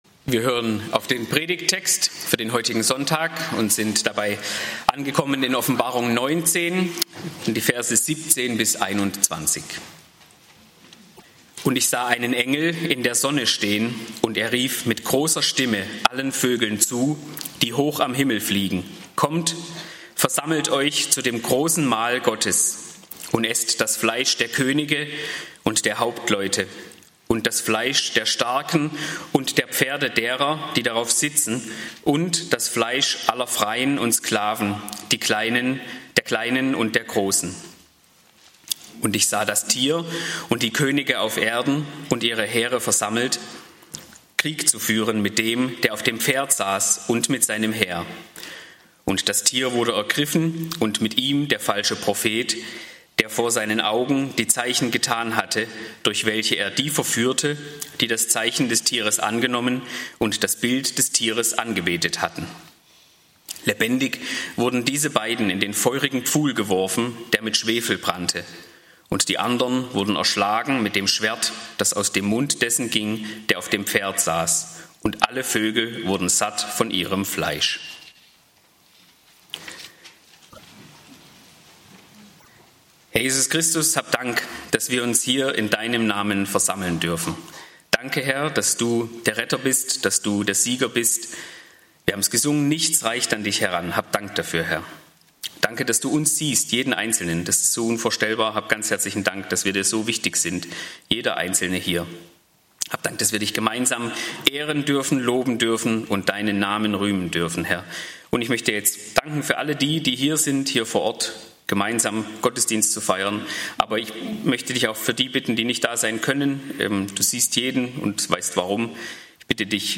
Plötzlich ist alles vorbei (Offb 19, 17-21) - Gottesdienst